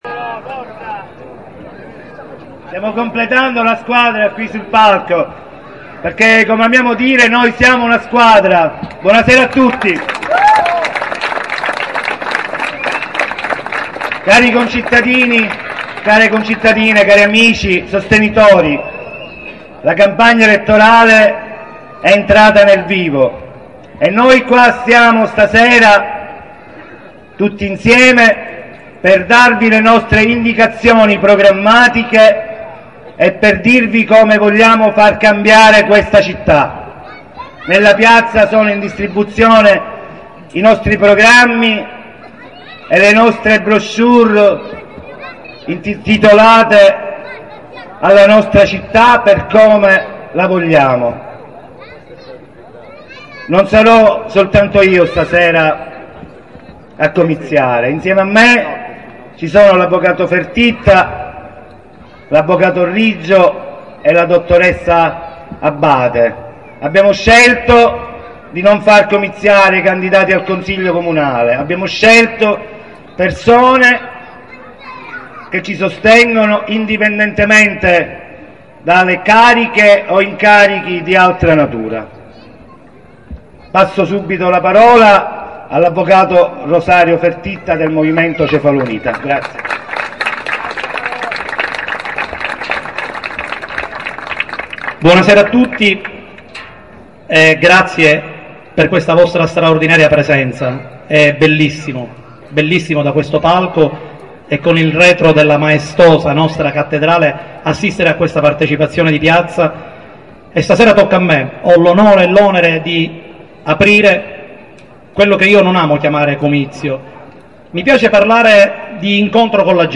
Comizio della lista "Cefalù Terzo Millennio"Hanno parlato nell'ordine